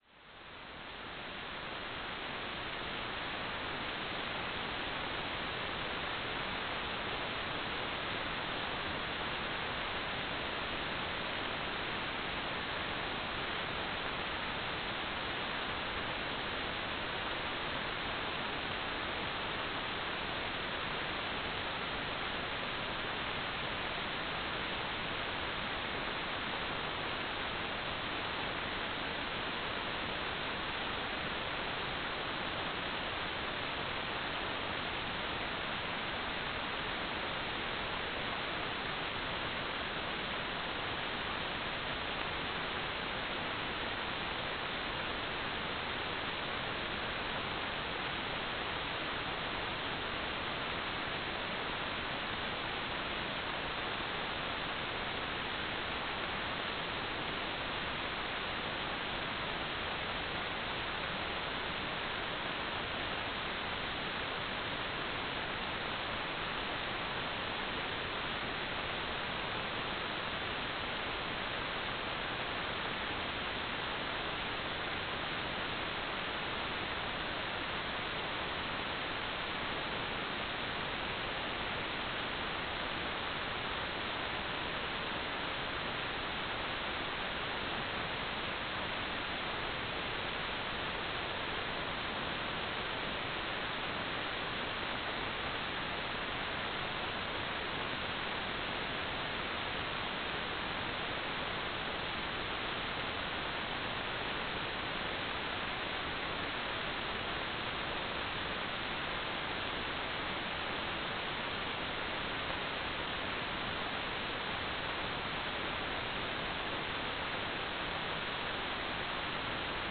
"transmitter_description": "carrier wave",
"transmitter_mode": "CW",